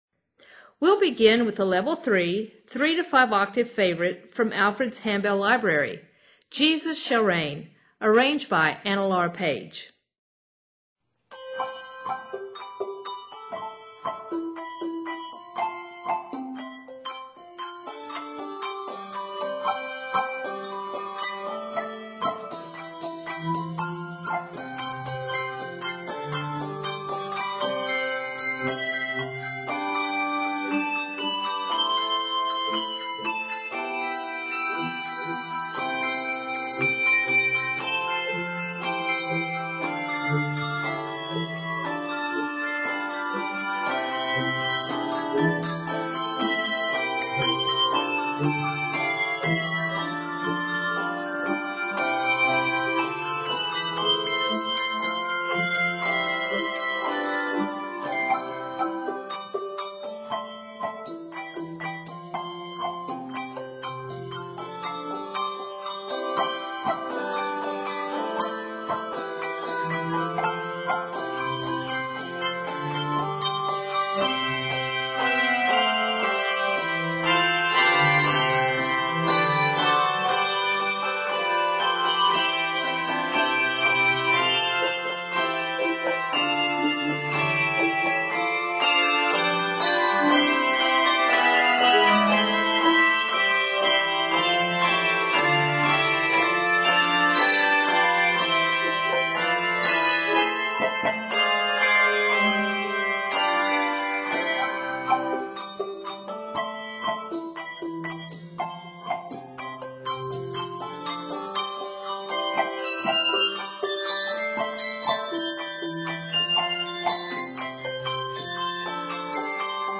is an exciting arrangement of the familiar hymn tune
for 3-5 octave handbell choir.